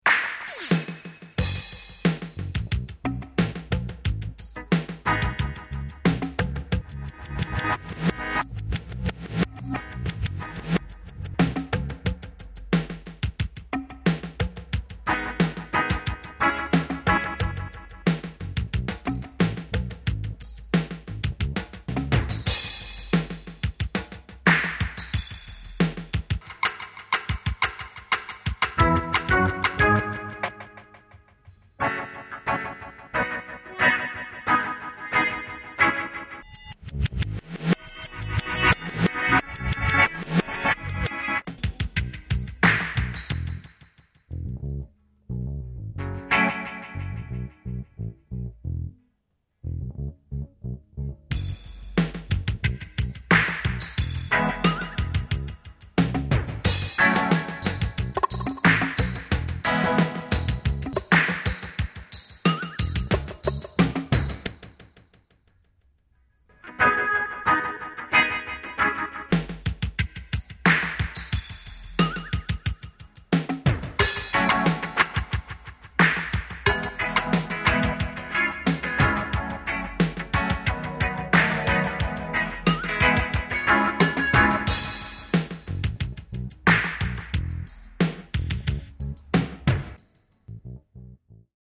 REGGAE DUB SECTION